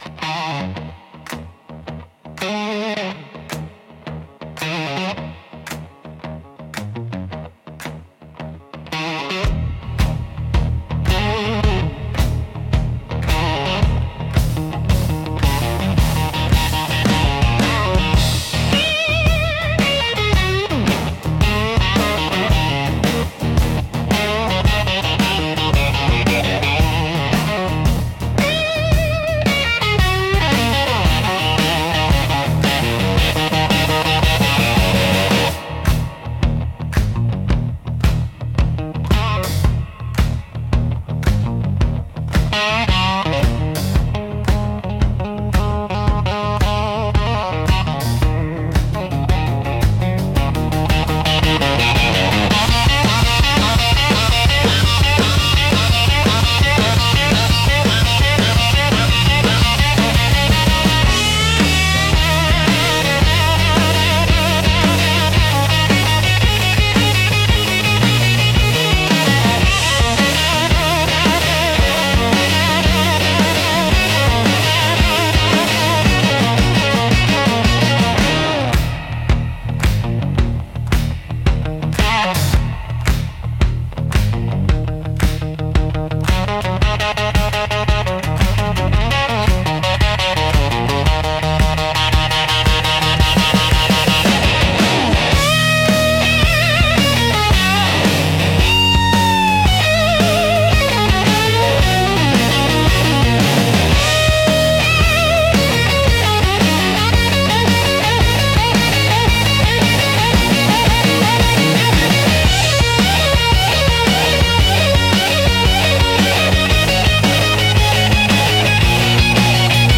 Instrumental - Crossfire Chase Sequence